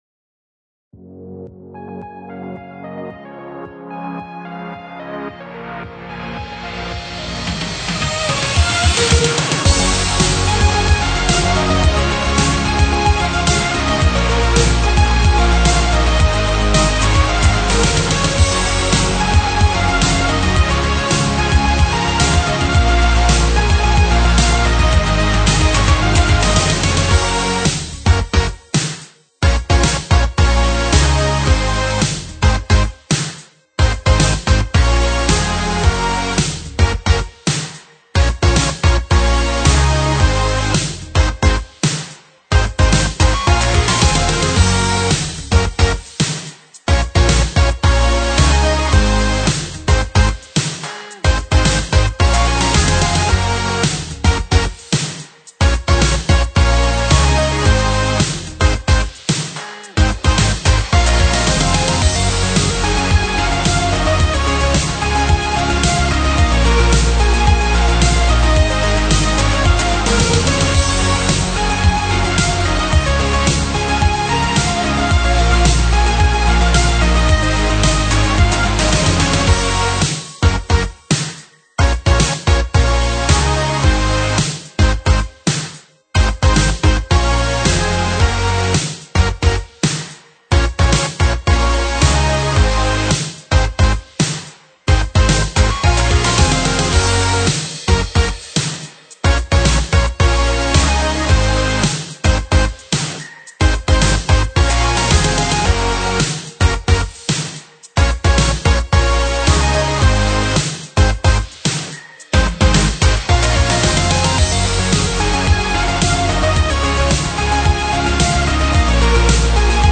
Sample Rate 采样率16-Bit Stereo 16位立体声, 44.1 kHz